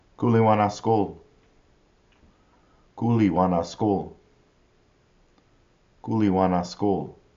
k-oli-wa-na-skol